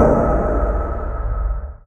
clang.ogg